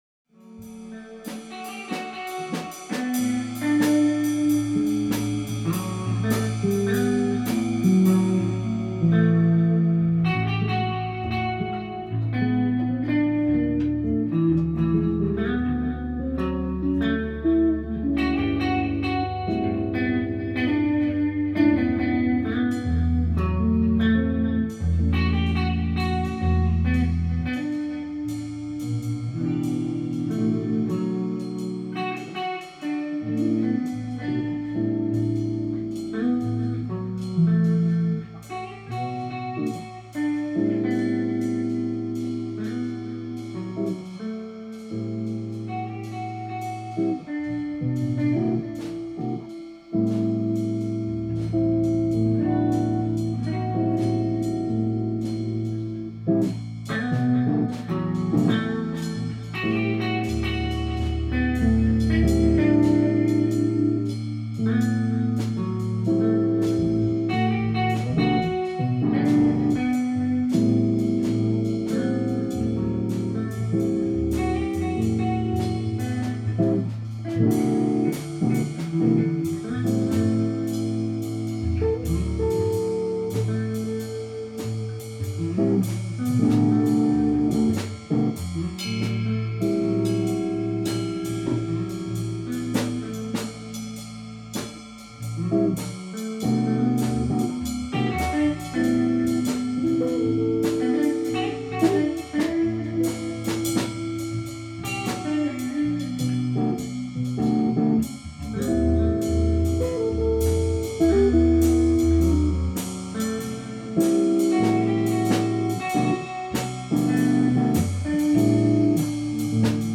A jam session